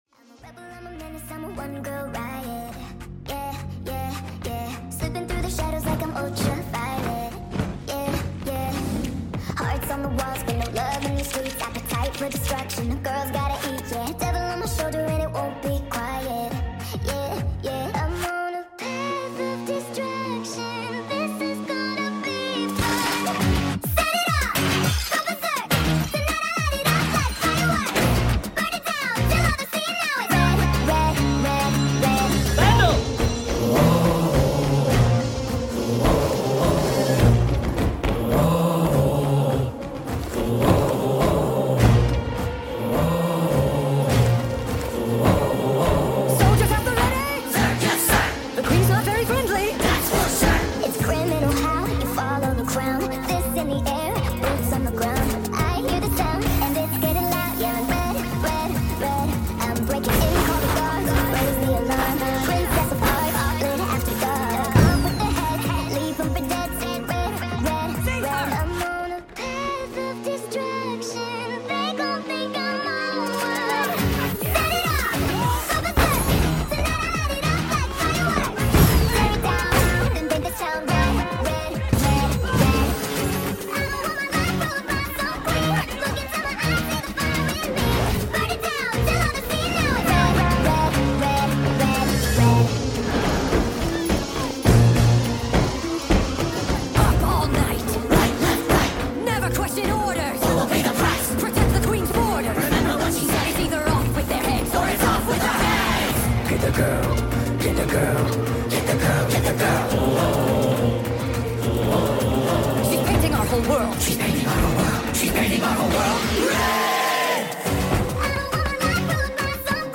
Full song + Sped up